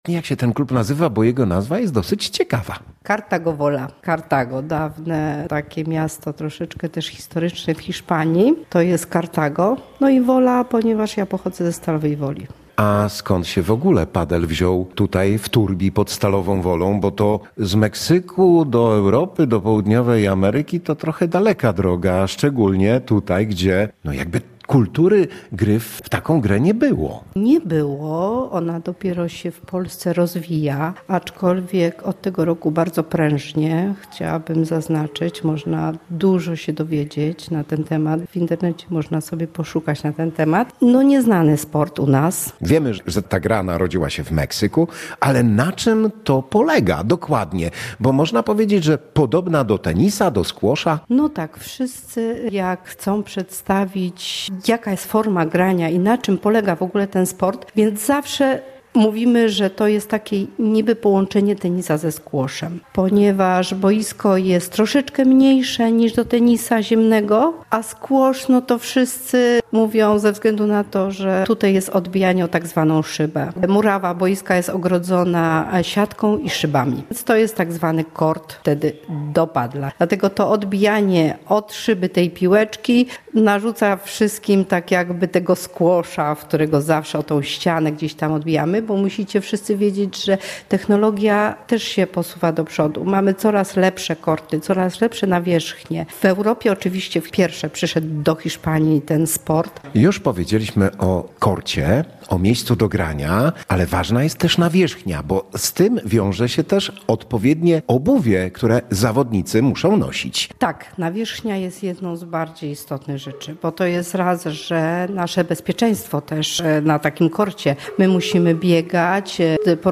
Czy znają państwo grę w padla? Jeśli nie, polecamy rozmowę na temat wyjątkowej i prawie nowej w Polsce gry, której miejscem narodzenia jest Meksyk.